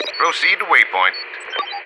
marine_order_move3.wav